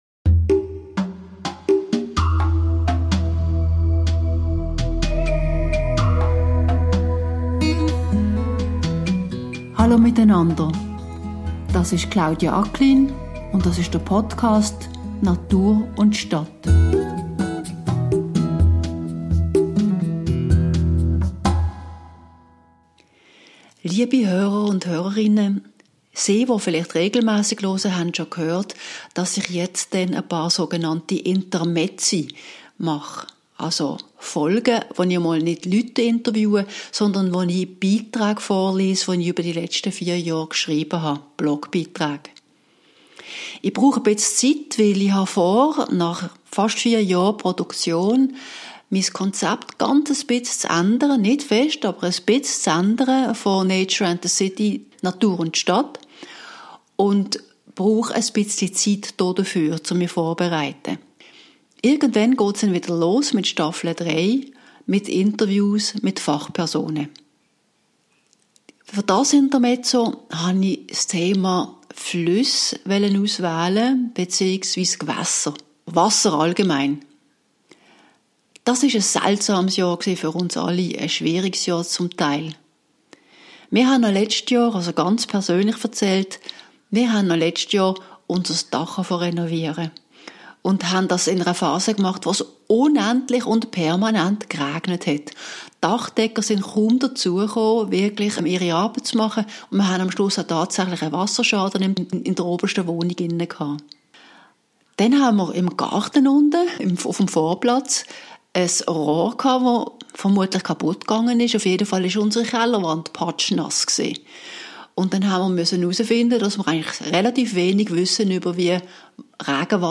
Für eine Weile reiche ich einige meiner zahlreichen Blog-Beiträge in gelesener Form dar.
Sie werden meine und keine AI-generierte Stimme hören, denn akustisch sind wir uns hoffentlich durch die vielen Podcast-Folgen recht nahe gekommen.